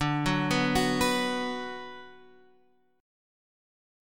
B5/D chord